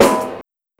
Snare (Freeee).wav